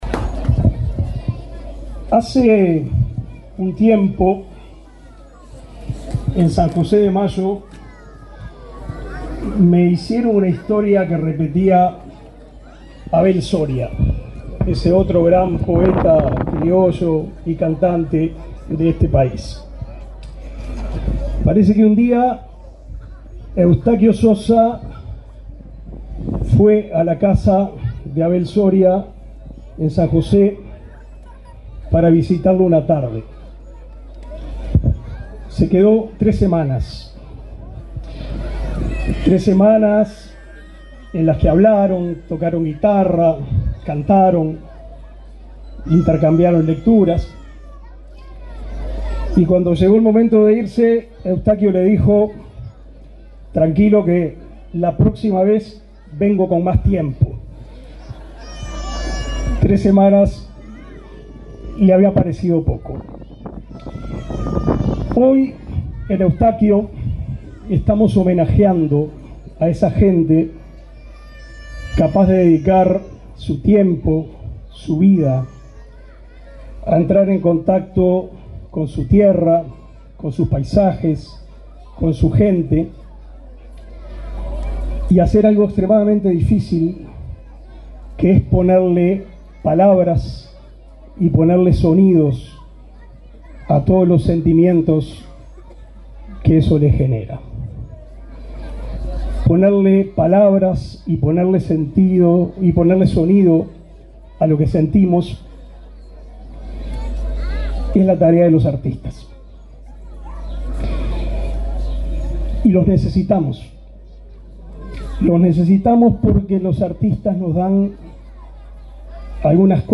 Palabras del ministro de Educación y Cultura, Pablo da Silveira
El ministro de Educación y Cultura, Pablo da Silveira, se expresó, este viernes 1.°, durante la presentación de una publicación sobre el cantautor